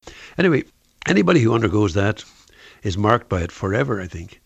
The deaccentuation of final sentence adverbials is, in any case, a very likely possibility, as the following examples show: